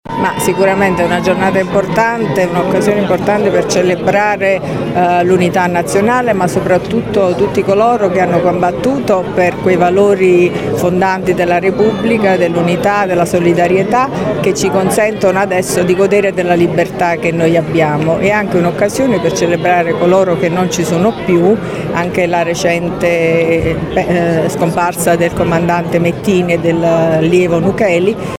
Le parole della Prefetta Ciaramella e degli altri protagonisti della Giornata